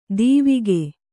♪ dīvige